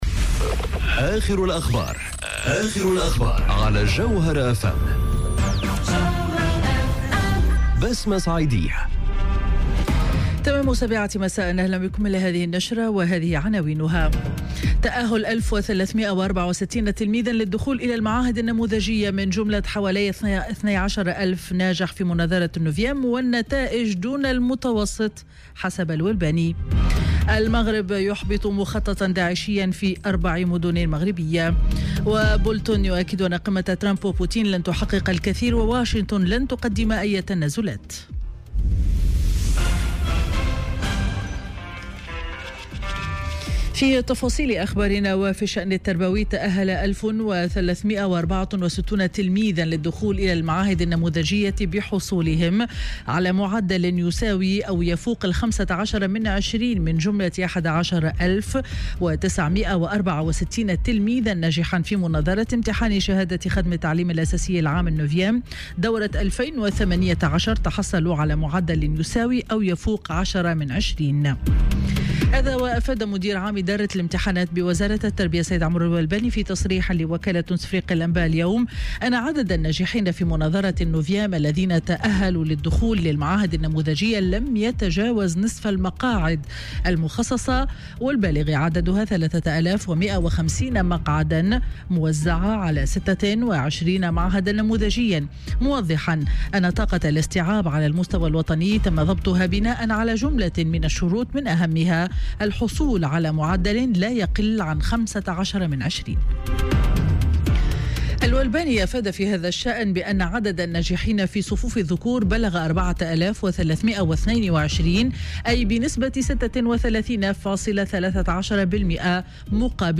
نشرة أخبار السابعة مساء ليوم الاثنين 2 جويلية 2018